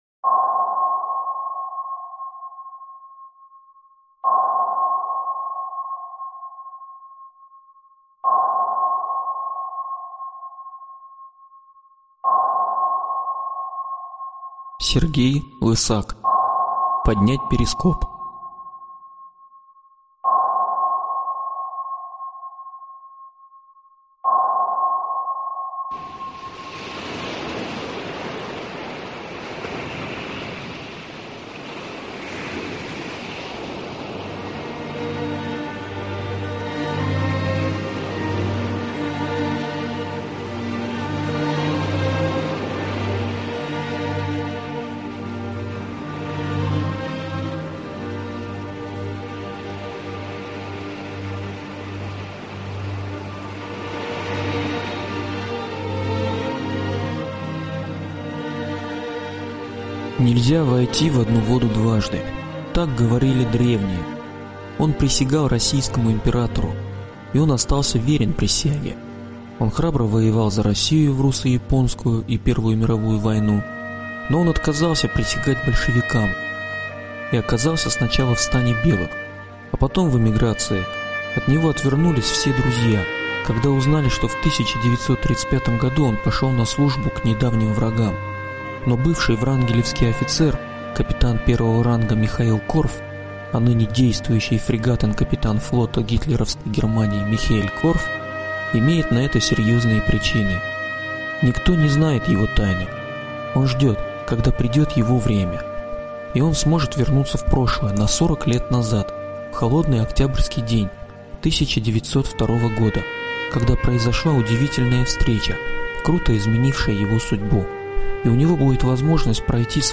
Аудиокнига Поднять перископ. Книга 1 | Библиотека аудиокниг